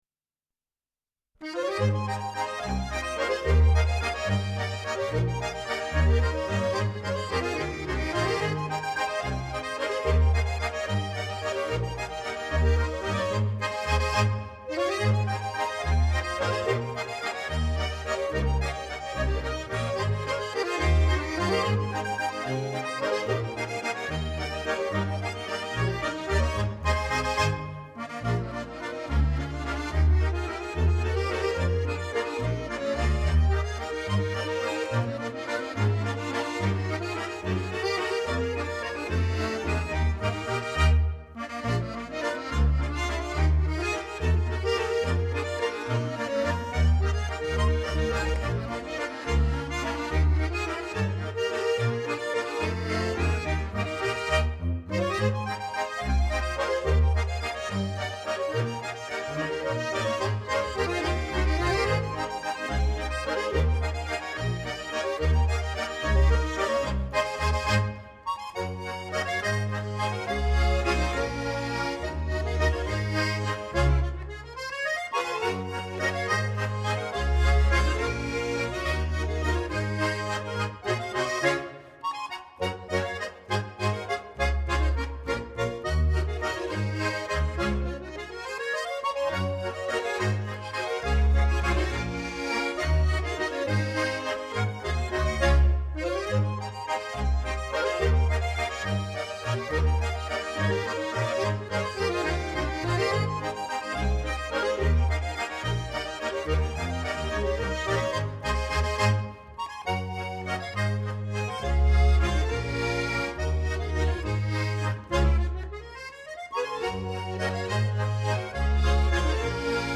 die etwas andere Volksmusik
Klarinette) aufgenommen 1995 im Tonstudio